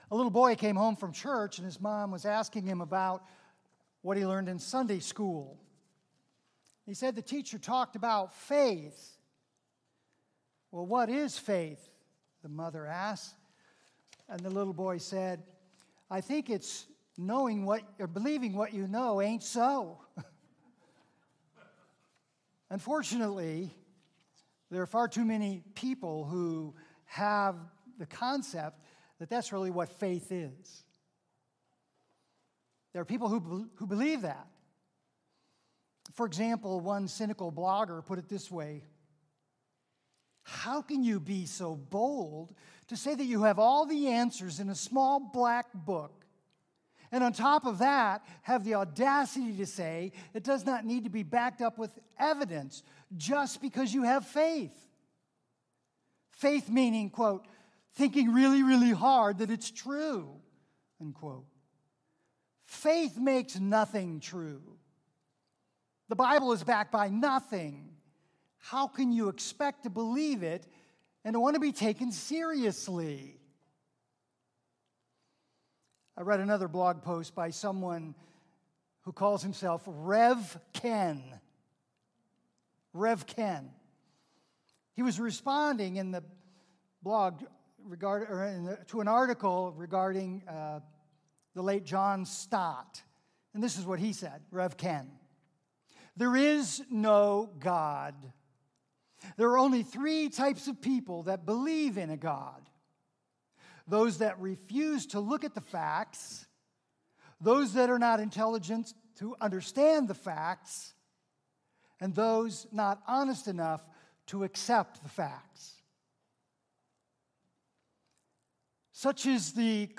Home New Here Events About Our Beliefs Next Steps Meet our Team Ministries Bless App Kids Youth Women Men Senior Adults Prayer Give Contact 40 Days of Prayer Previous Sermons Isn’t religion based on blind faith?